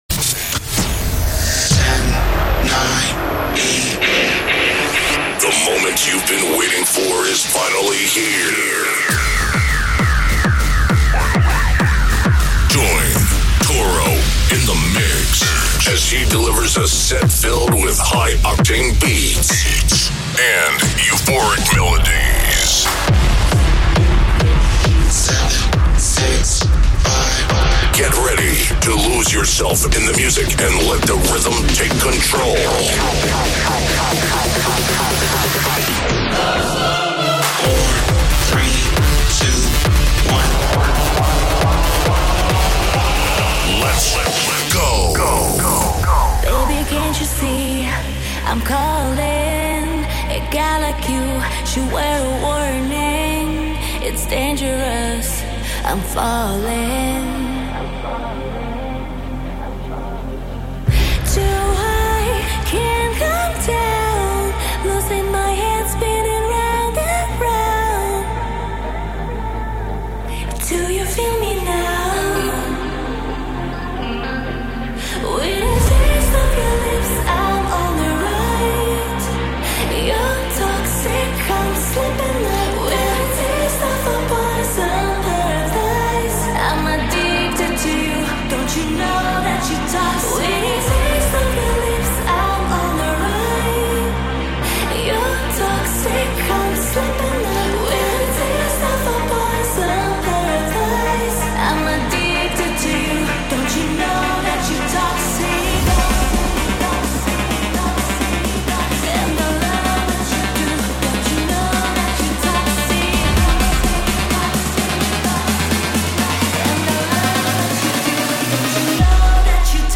" the ultimate dance and trance music podcast.